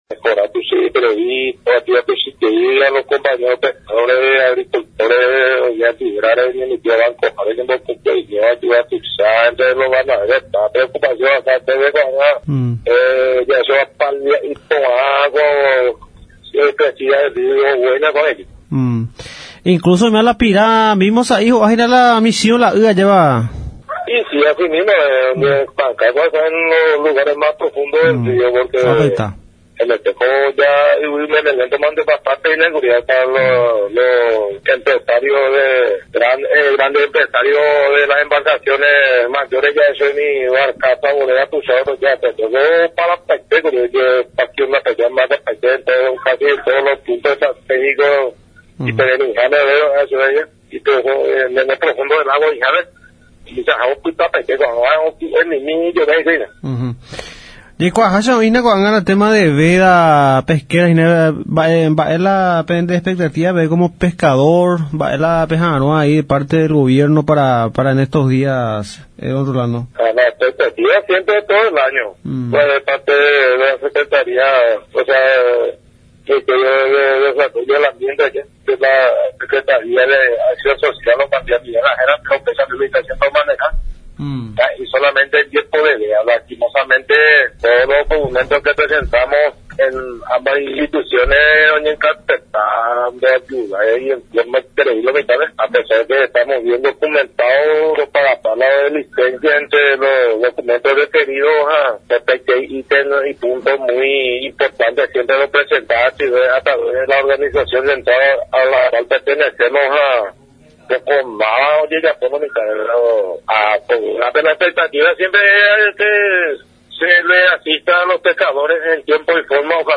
en comunicación con Radio Nacional San Pedro 105.9 FM